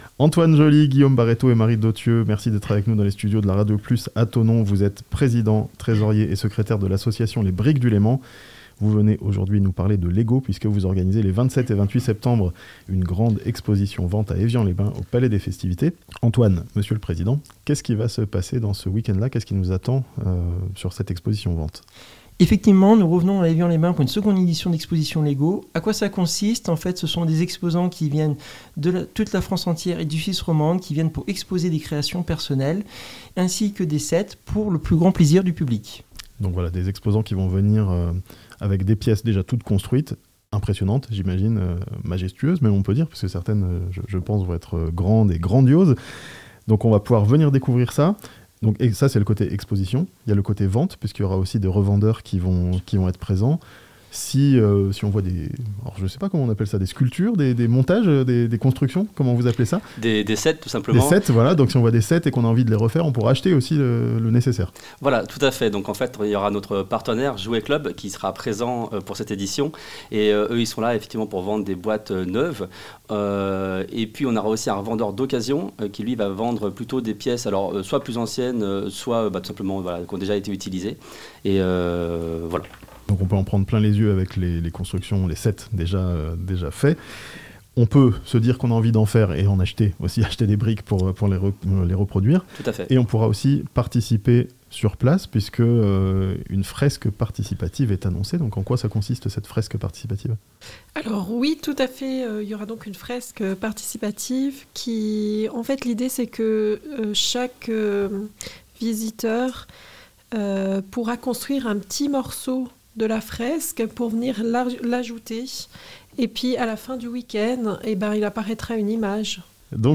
Les amoureux des briques Lego ont rendez-vous à Evian (interview)